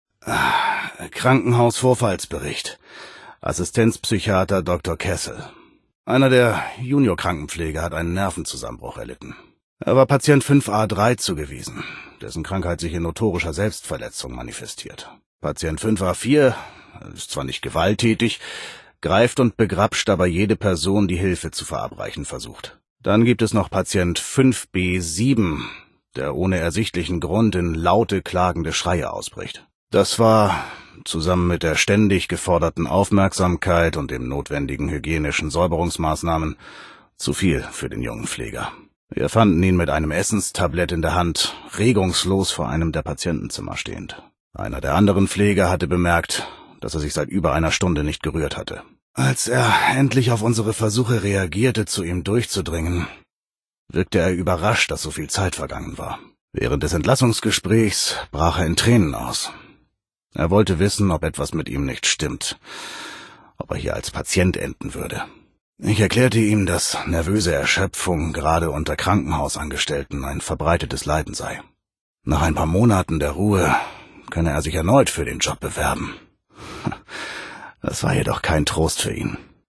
Fallout 76: Audio-Holobänder